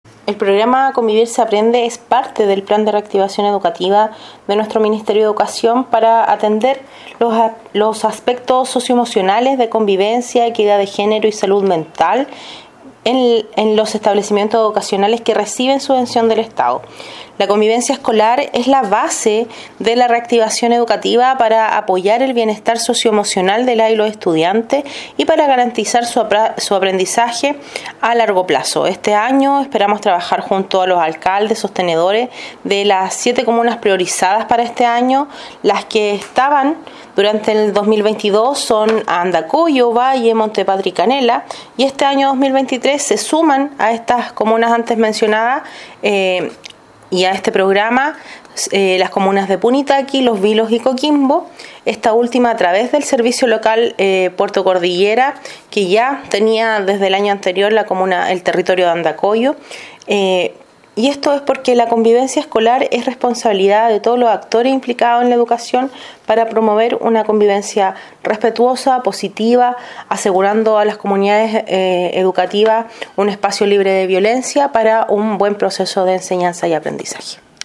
La Seremi de Educación de Coquimbo, Cecilia Ramírez Chávez enfatizó que
1_-Cecilia-Ramirez-Chavez-Seremi-de-Educacion_.mp3